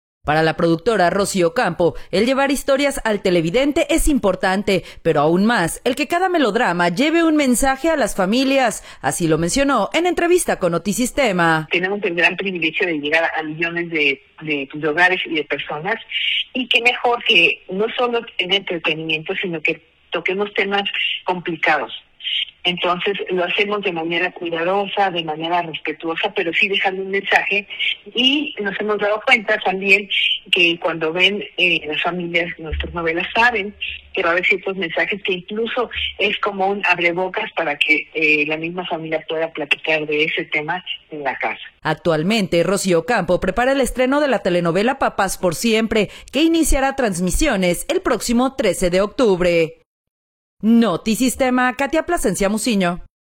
Para la productora Rosy Ocampo el llevar historias al televidente es importante, pero aún más el que cada melodrama lleve un mensaje a las familias, así lo mencionó en entrevista con Notisistema.